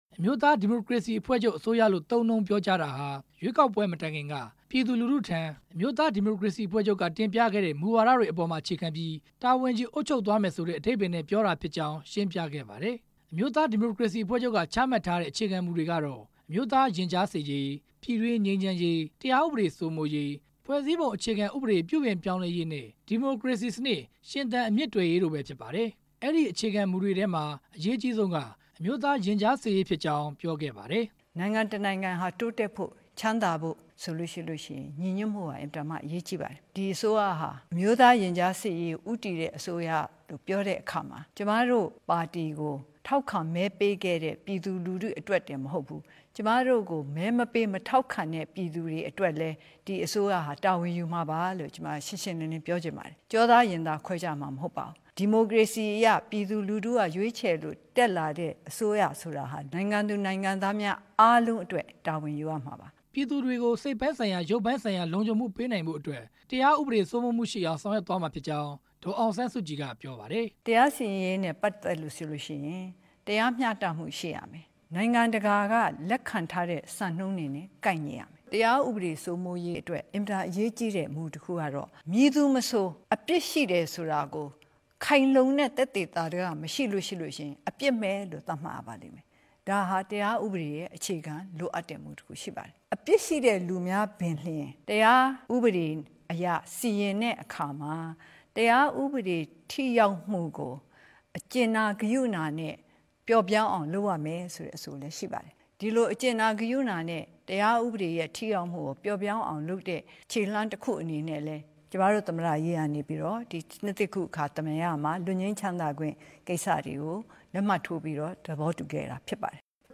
နိုင်ငံတော်အတိုင်ပင်ခံပုဂ္ဂိုလ် ဒေါ်အောင်ဆန်းစုကြည်က ဒီနေ့ တိုင်းပြည်ကို နှစ်သစ်ကူးမိန့်ခွန်းပြောကြားခဲ့ပါတယ်။